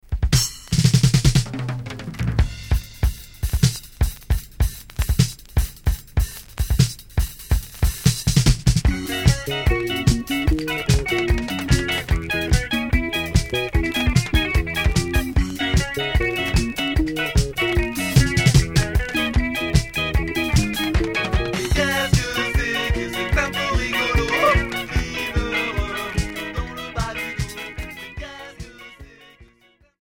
Rock ska